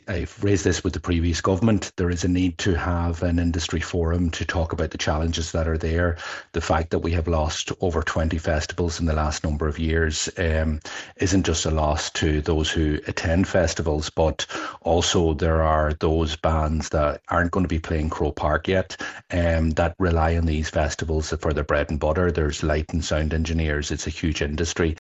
He’s urging the Government to sit down with gig organisers to work out a way to save the industry: